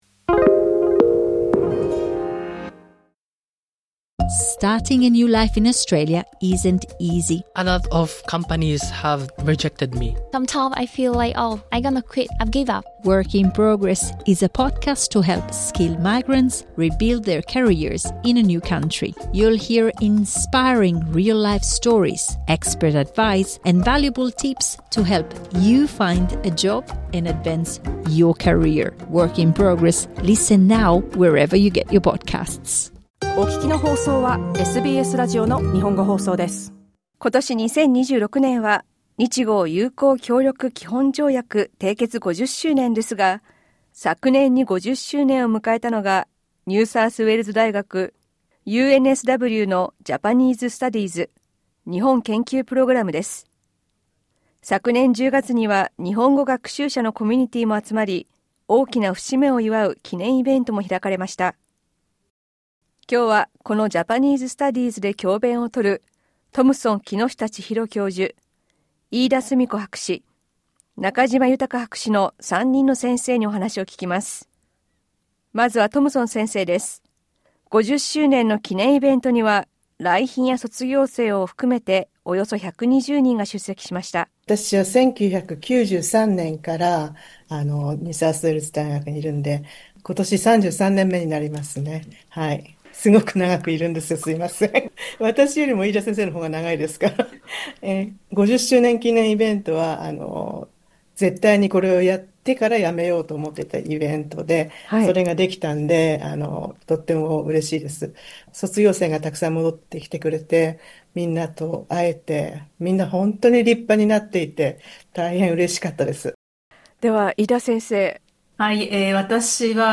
詳しくはインタビューから。